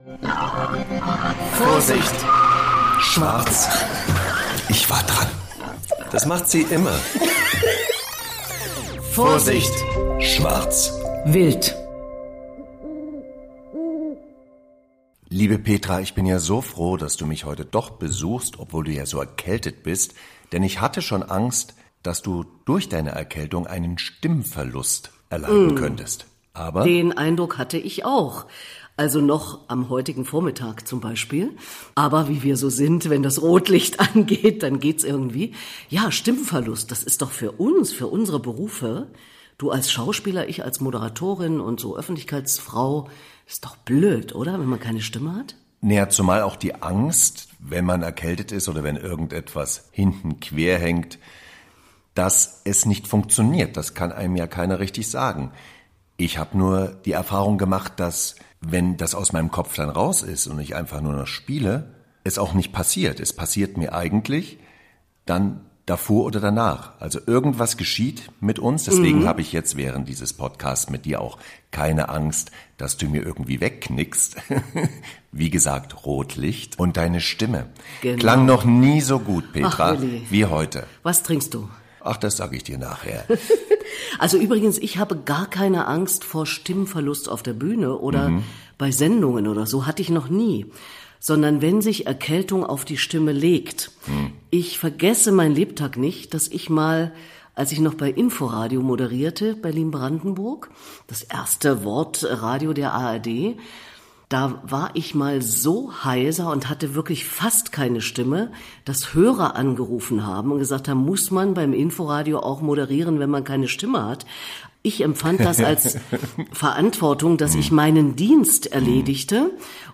ist dieses Mal ziemlich erkältet